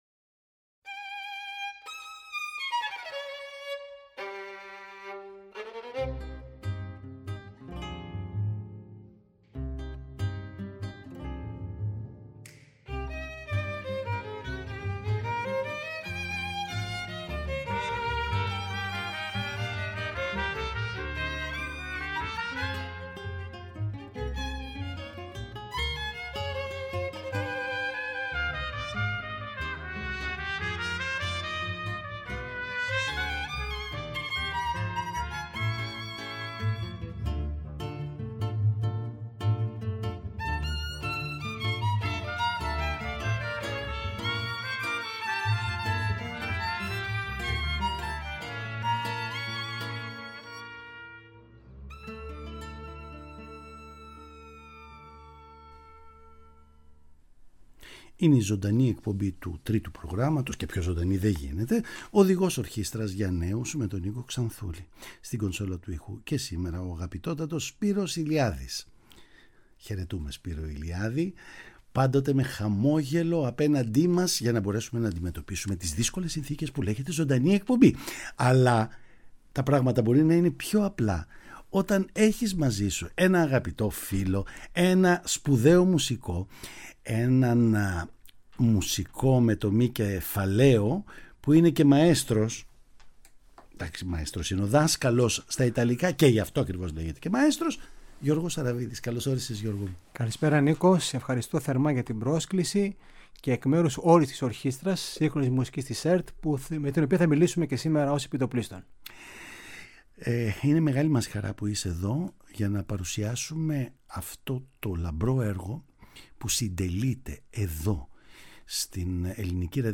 Παραγωγή-Παρουσίαση: Νίκος Ξανθούλης
Συνεντεύξεις